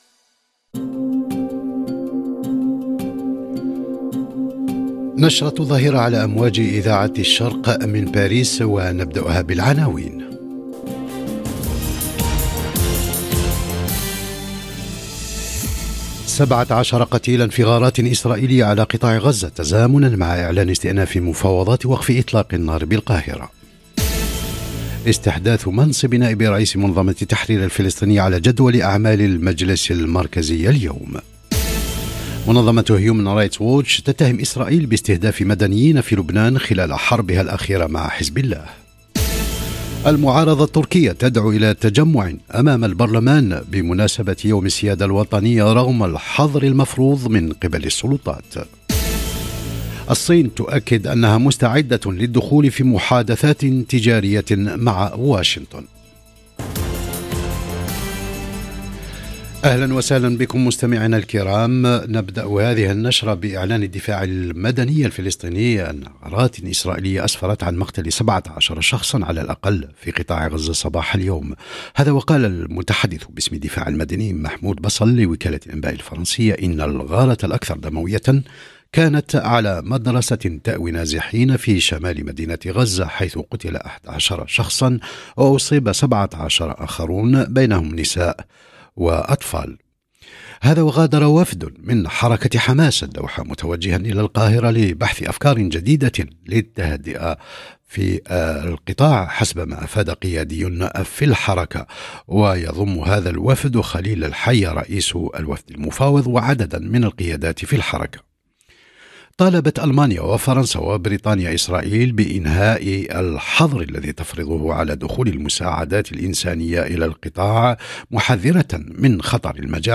نشرة